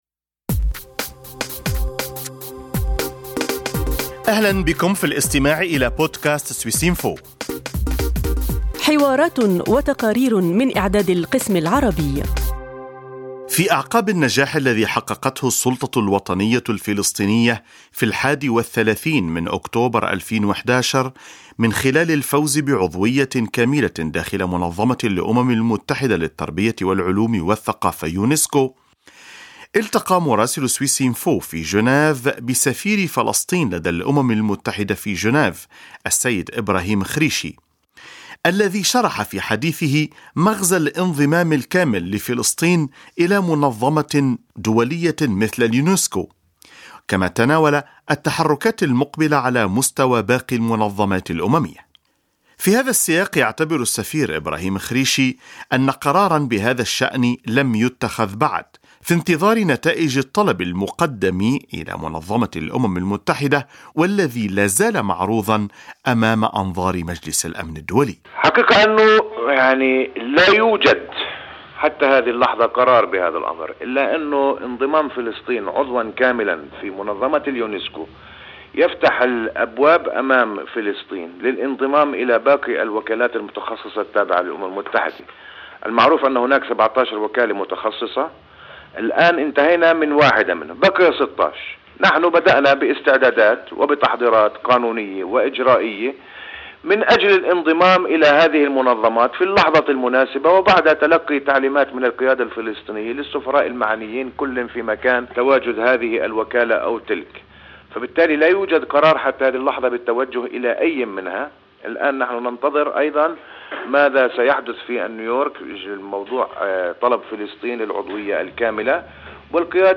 يشرح السفير الفلسطيني لدى الأمم المتحدة في جنيف أبعاد الإنضمام الكامل لليونسكو والتحركات المحتملة على مستوى باقي المنظمات الأممية.